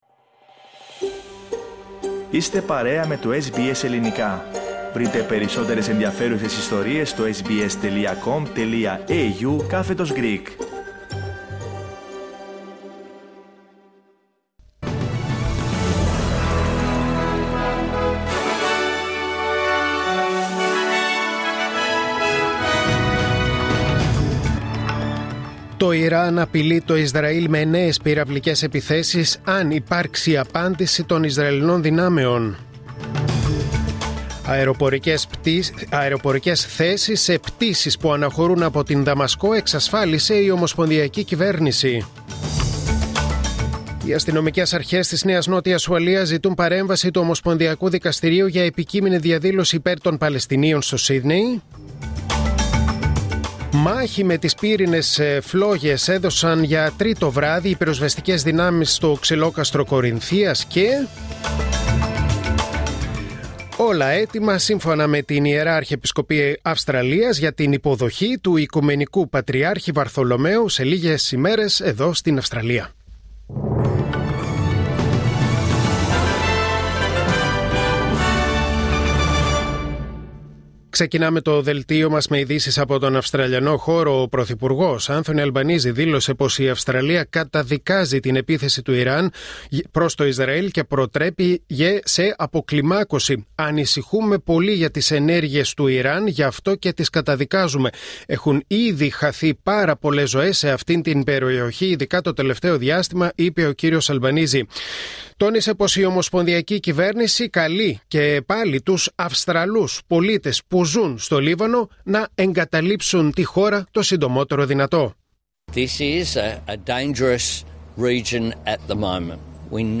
Δελτίο Ειδήσεων Τετάρτη 02 Οκτωβρίου 2024